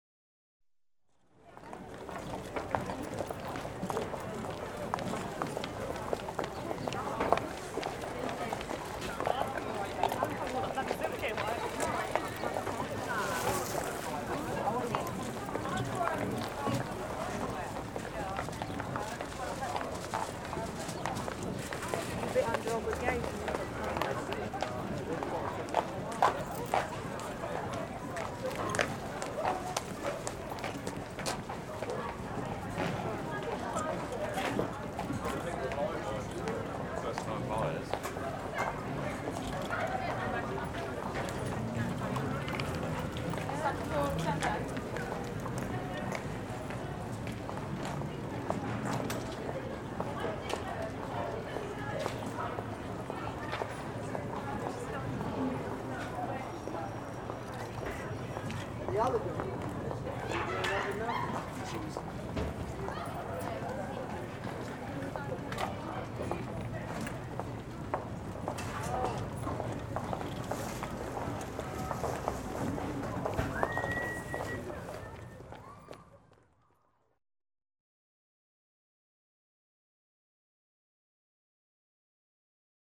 Звуки рынка, базара
Здесь вы найдете шум торгашей, гул покупателей, звон монет и другие аутентичные эффекты восточного или городского базара.
Шум и суета рыночной толпы